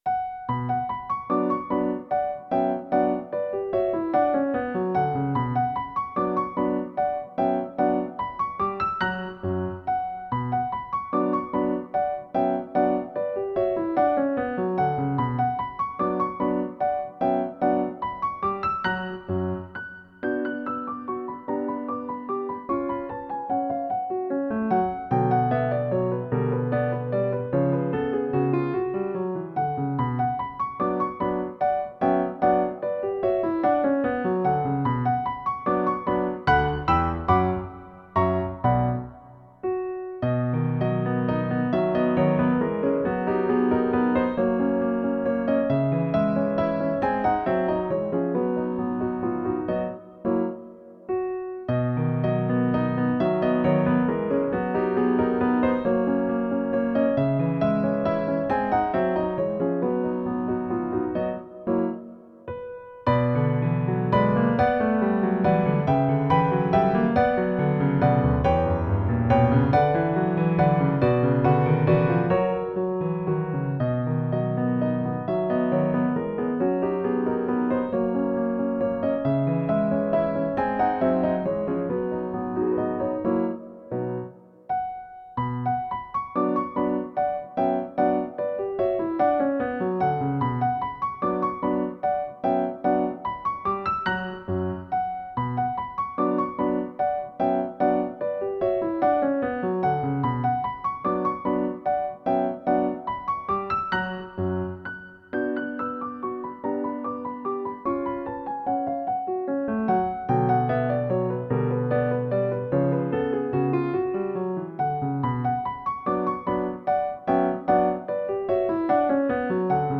クラシック曲